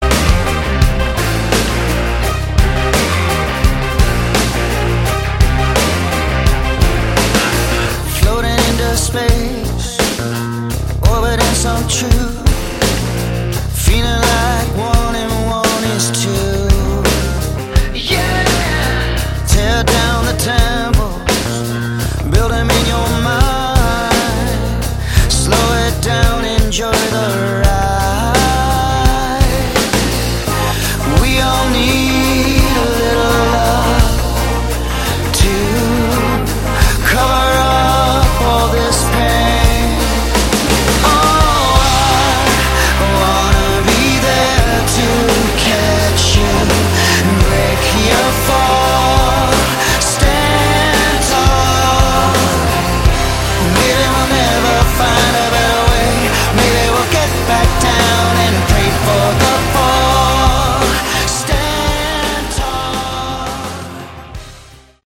Category: Melodic Rock
Far too mellow.